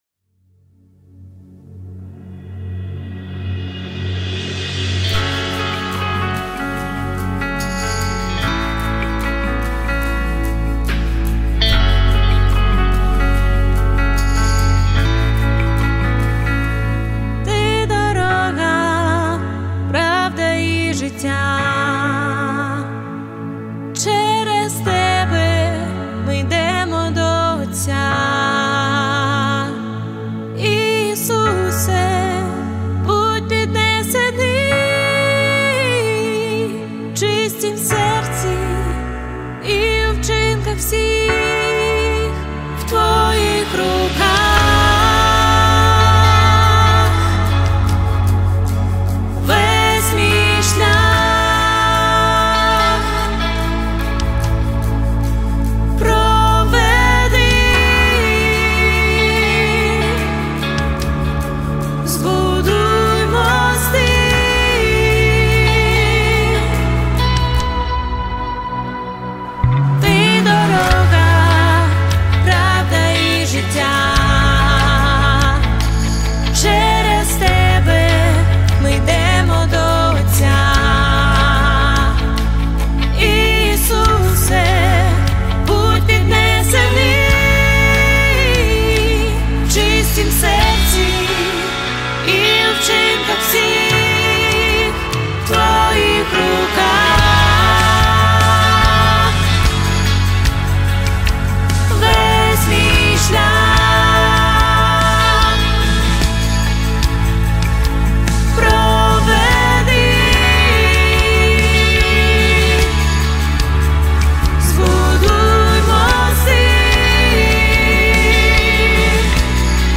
78 просмотров 20 прослушиваний 7 скачиваний BPM: 73